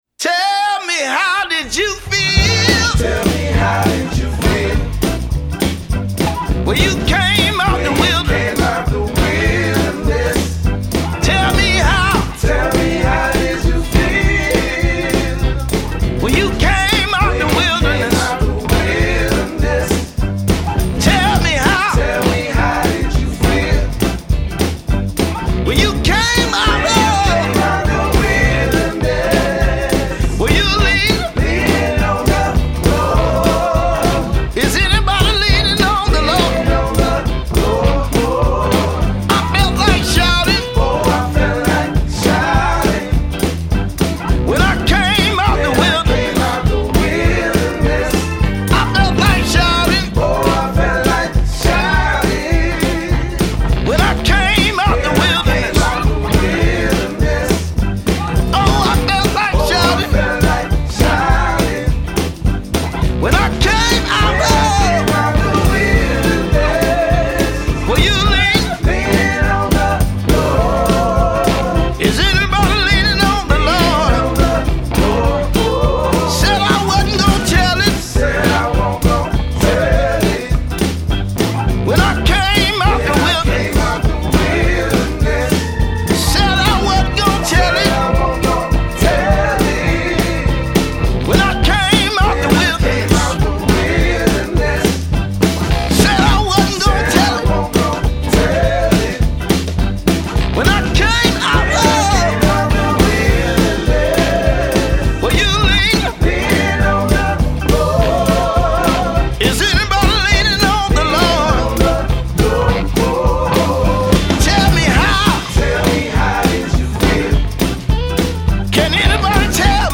Un disque à l’ancienne à la gloire de Dieu et du groove….